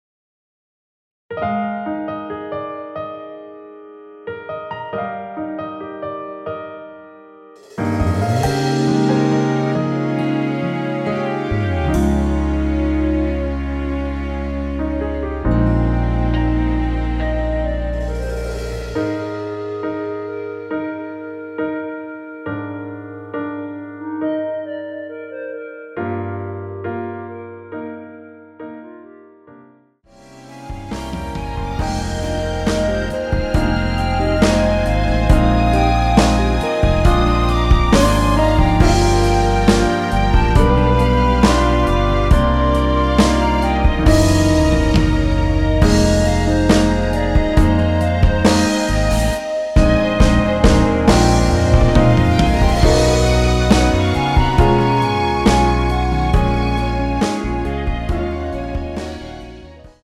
원키 1절후 후렴으로 진행 되는 멜로디 포함된 MR입니다.(미리듣기 확인)
Eb
앞부분30초, 뒷부분30초씩 편집해서 올려 드리고 있습니다.
중간에 음이 끈어지고 다시 나오는 이유는